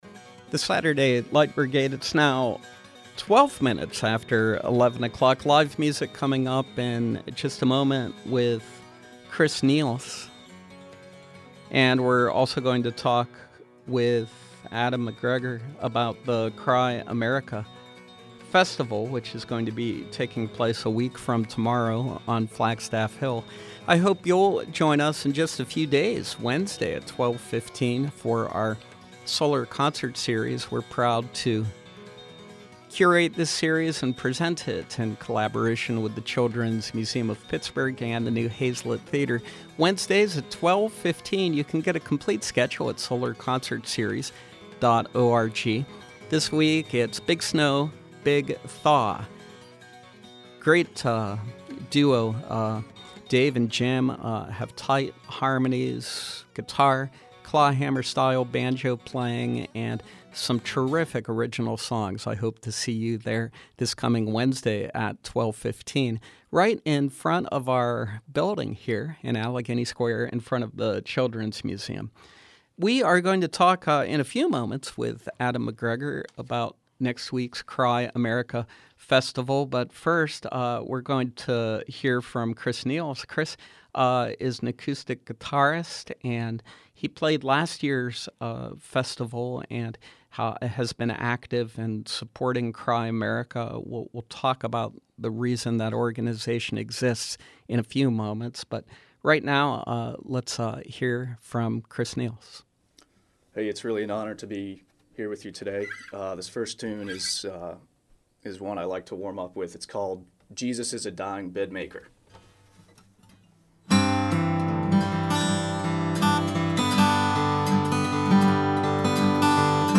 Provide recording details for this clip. performs live in our studios